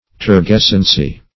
Search Result for " turgescency" : The Collaborative International Dictionary of English v.0.48: Turgescence \Tur*ges"cence\, Turgescency \Tur*ges"cen*cy\, n. [Cf. F. turgescence.
turgescency.mp3